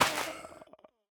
Minecraft Version Minecraft Version snapshot Latest Release | Latest Snapshot snapshot / assets / minecraft / sounds / block / soul_sand / break7.ogg Compare With Compare With Latest Release | Latest Snapshot